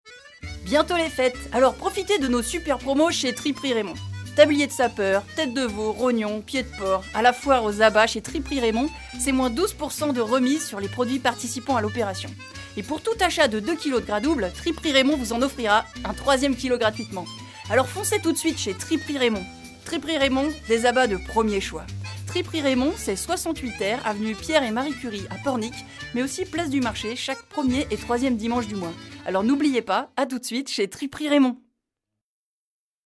Des voix-off